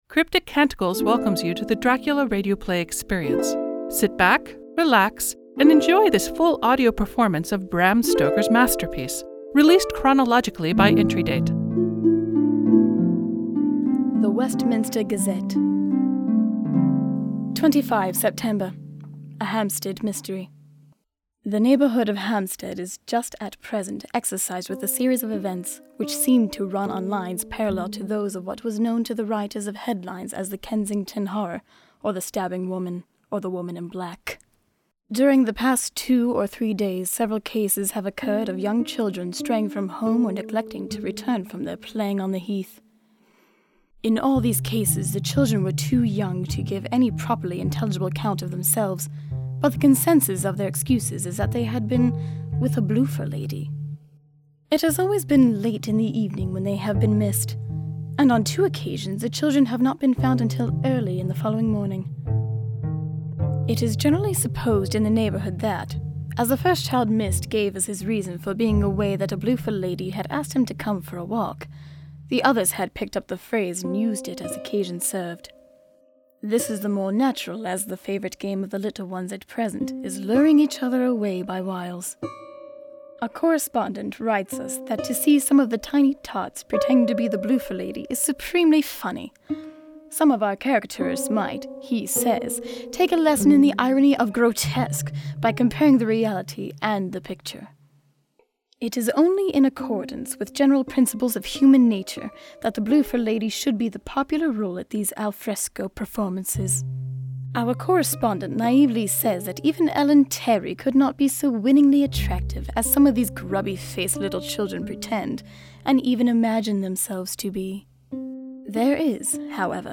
Cast Journalist